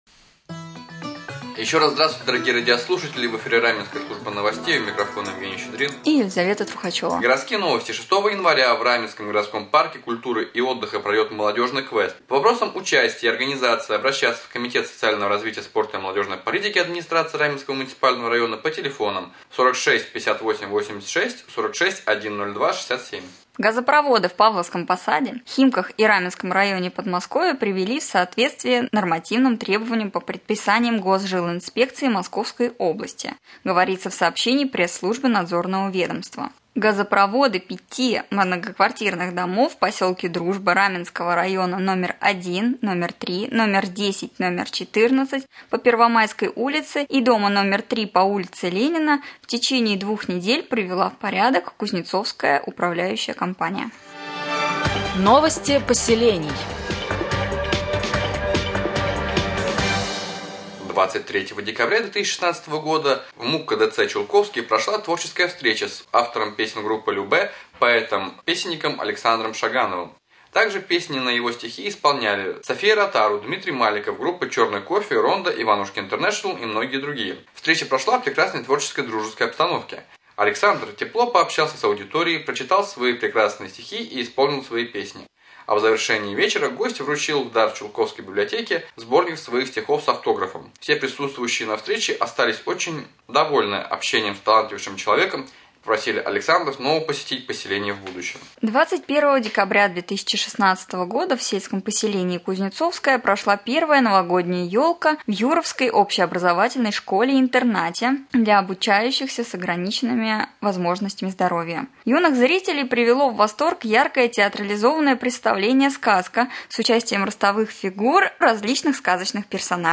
2. Новогодний прямой эфир